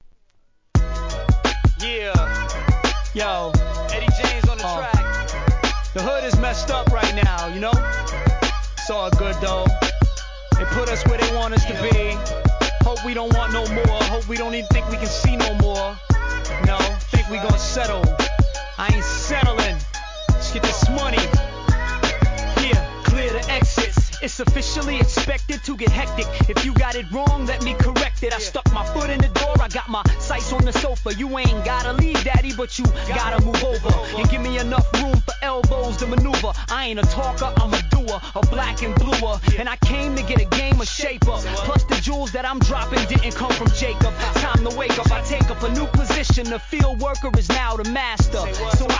HIP HOP/R&B
ソウルフルな45回転サンプリングが光る